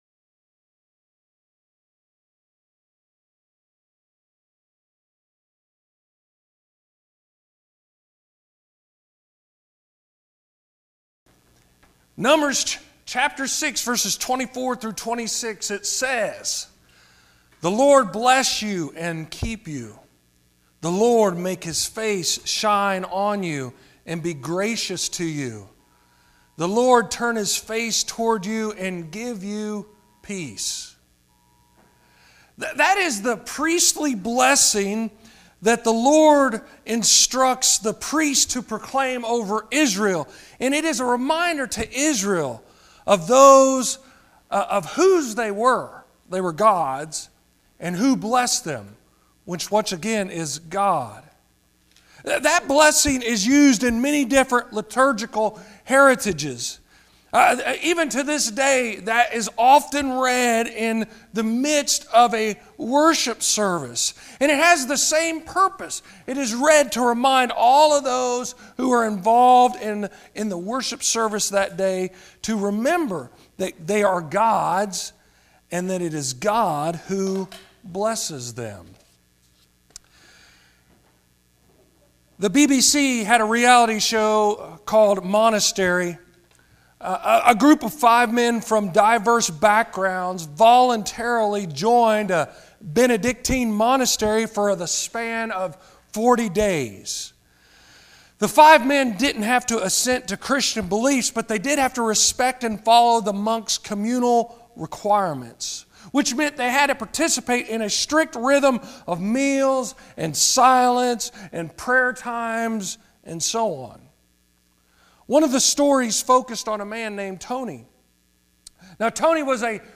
Sermons on THE Sermon Begin with Blessing Speaker